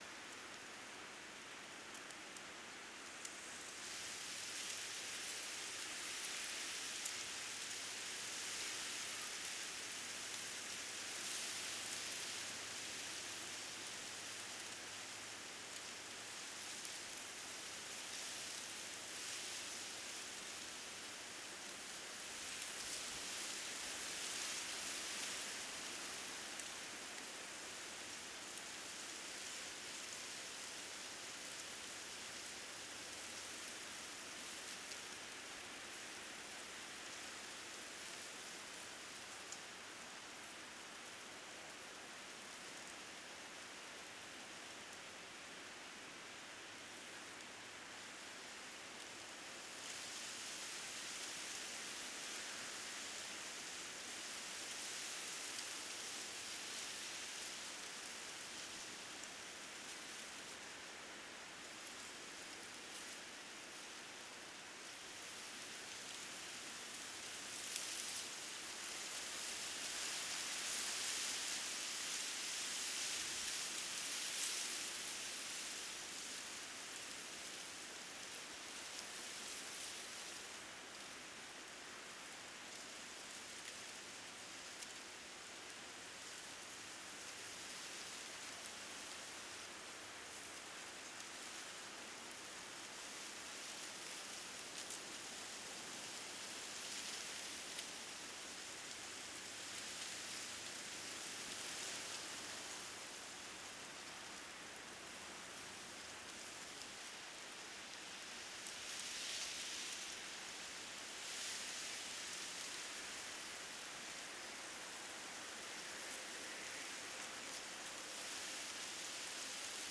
WIND_THRU_GRASS.R.wav